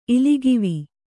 ♪ iligivi